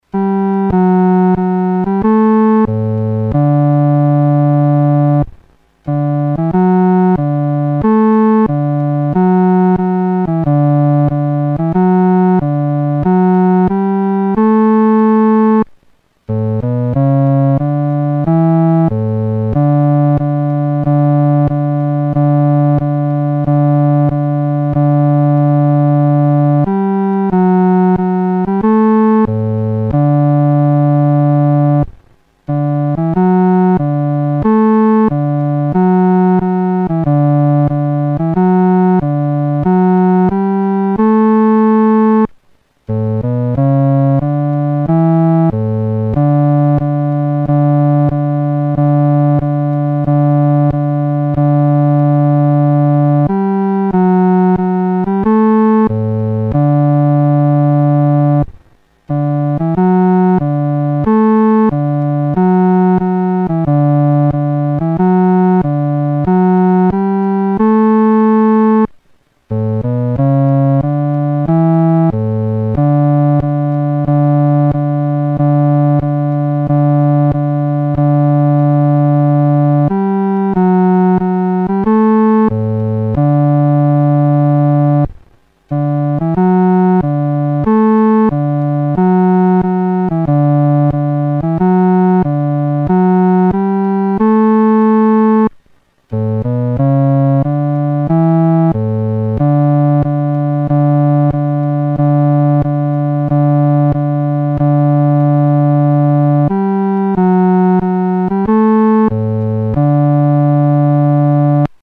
独奏（第四声）
牧人闻信-独奏（第四声）.mp3